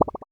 bubble_effect_01.wav